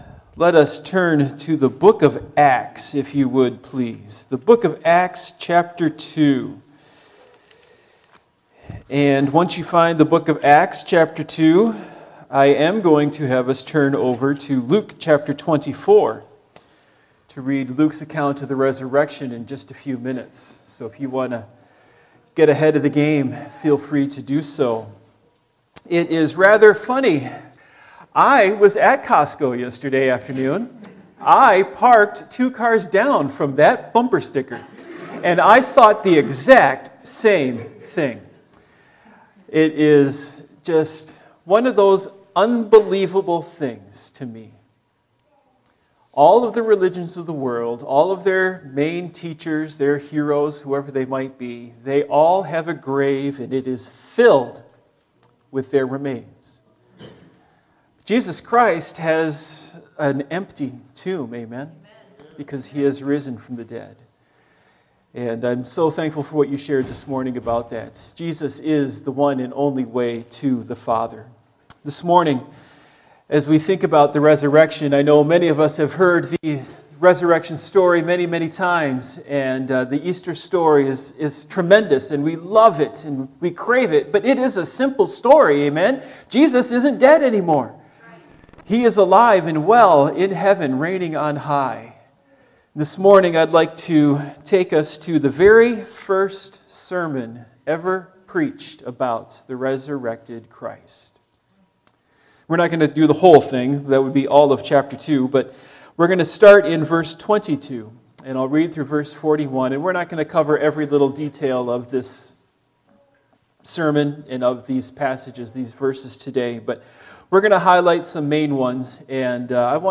Acts 2:22-41 Service Type: Morning Worship Topics